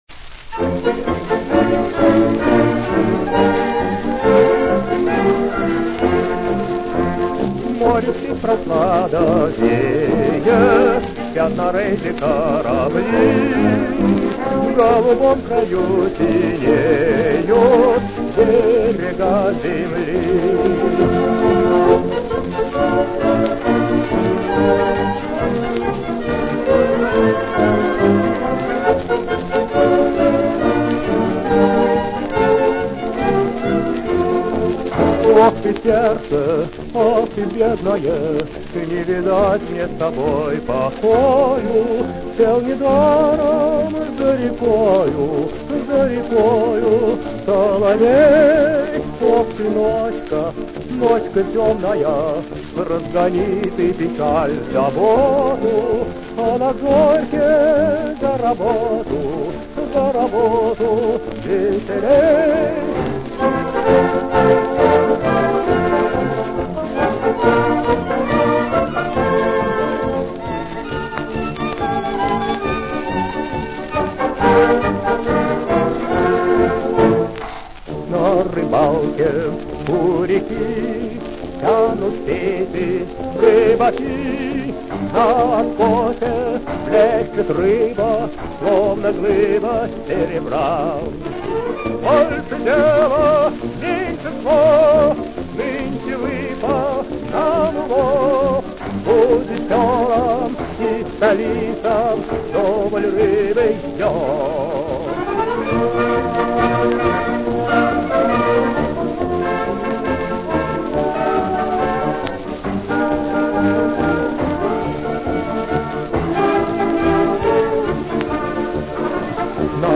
Интересная довоенная запись.